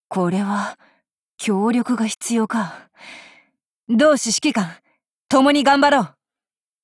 贡献 ） 协议：Copyright，人物： 碧蓝航线:基洛夫·META语音 您不可以覆盖此文件。